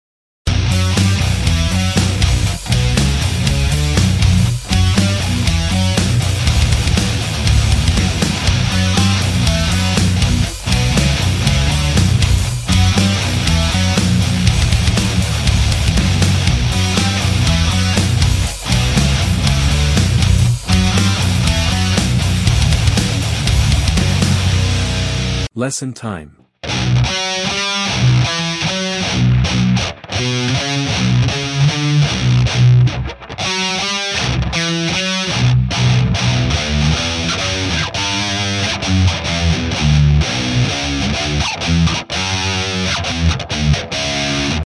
It's performed on a seven-string guitar, where the seventh string is tuned to B. This Is My Fourth Riff Sound Effects Free Download.